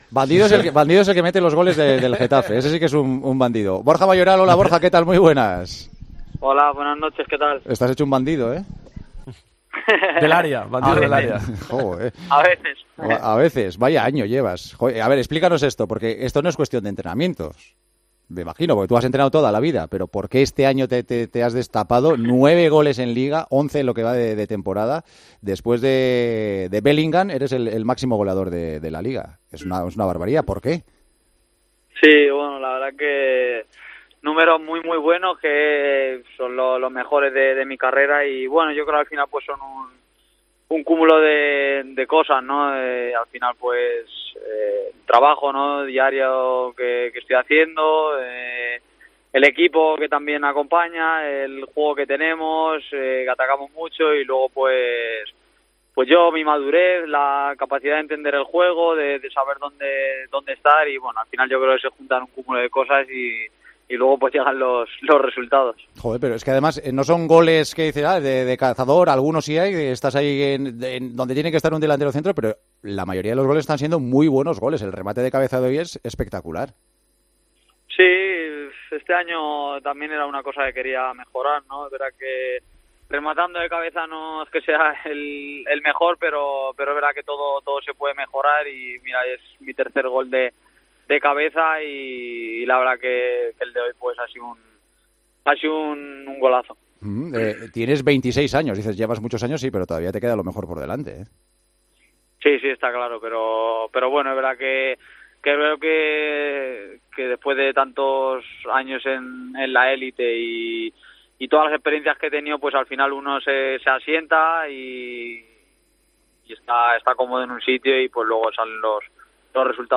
El gran protagonista de la noche se pasó por El Partidazo de COPE en el comienzo del programa de este viernes e intentó explicar a qué se debe su cambio en el registro goleador.